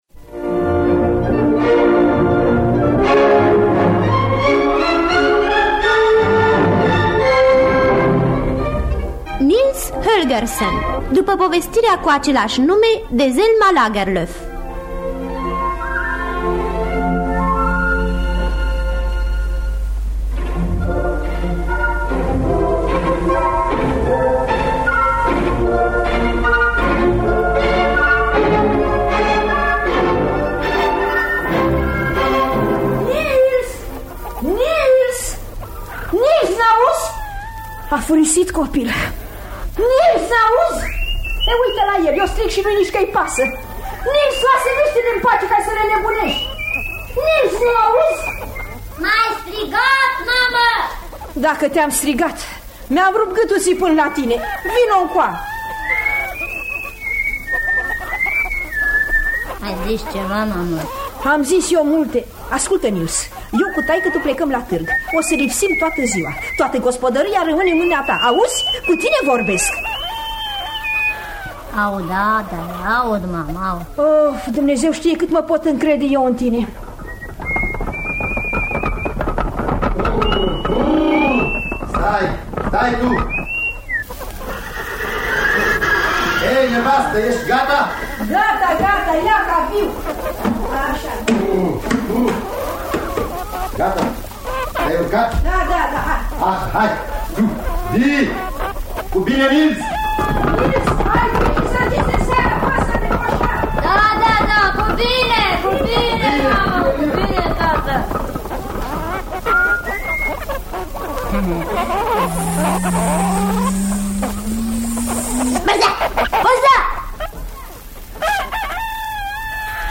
Dramatizarea radiofonică